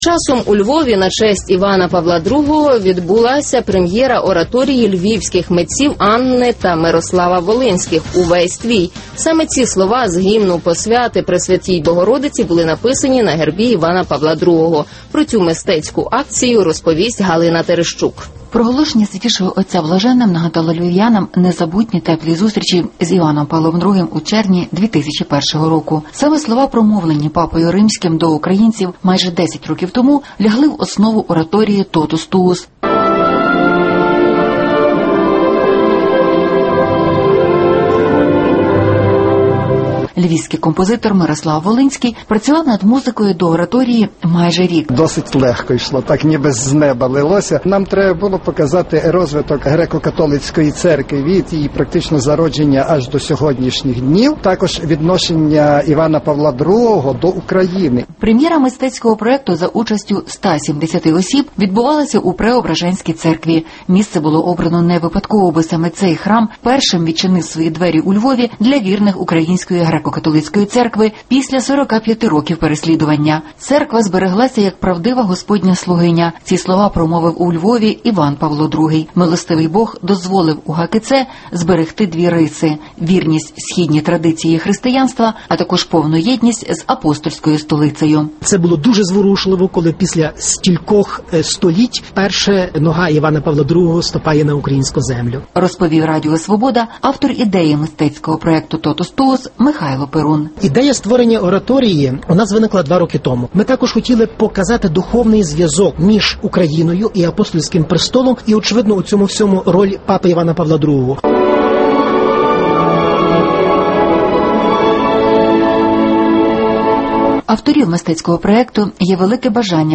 «Totus tuus» – у Львові звучала ораторія на честь блаженного Івана Павла ІІ